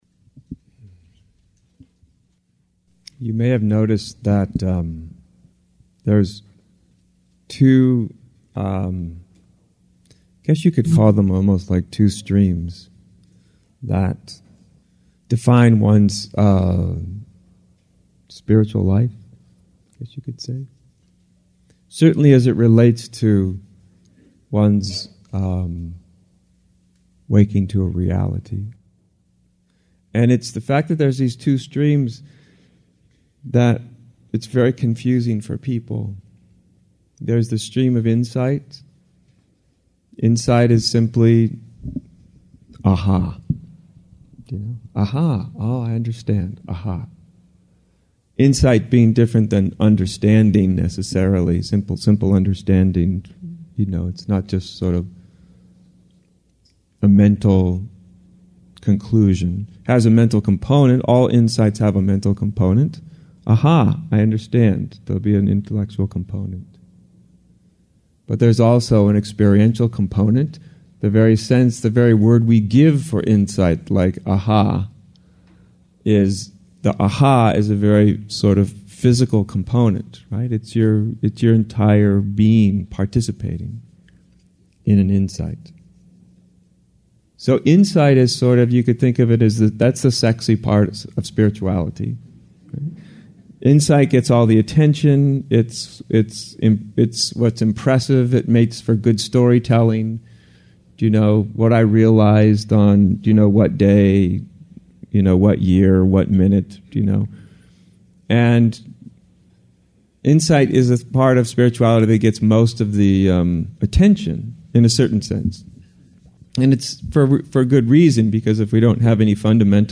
How does it feel to have no “self” anymore ? A recent talk by Adyashanti